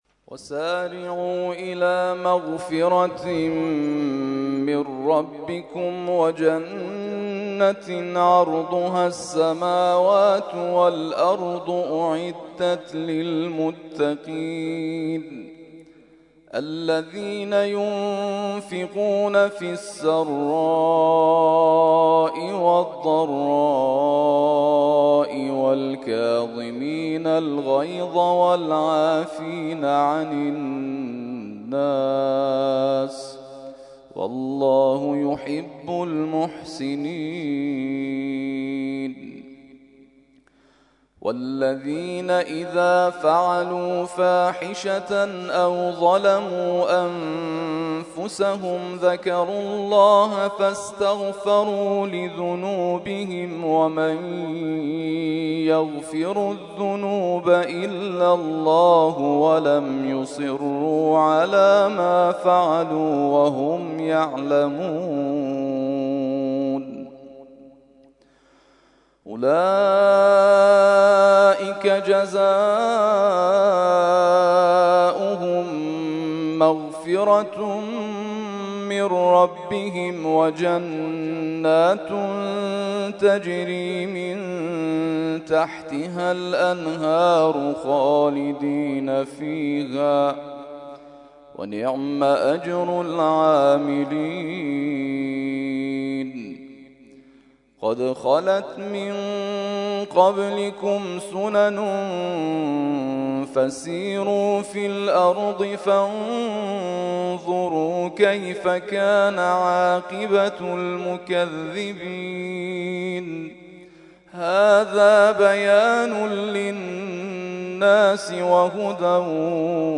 ترتیل خوانی جزء ۴ قرآن کریم در سال ۱۳۹۵